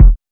KICK.72.NEPT.wav